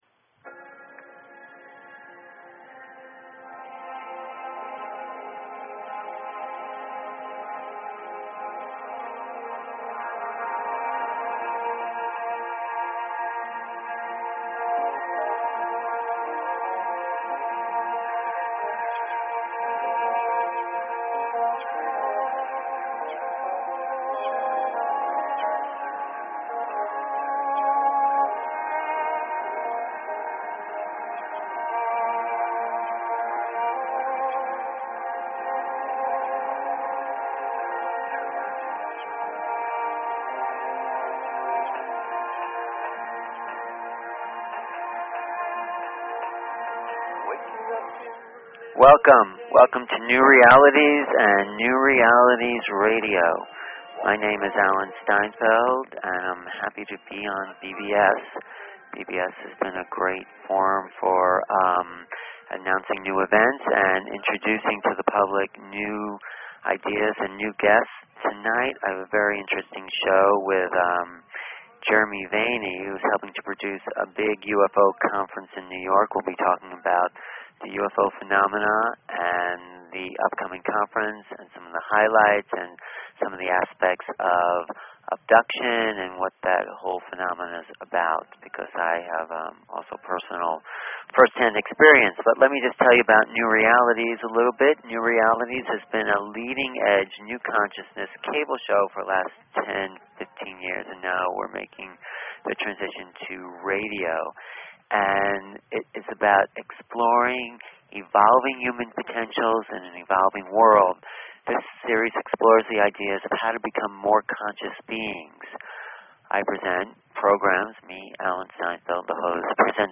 New Realities Talk Show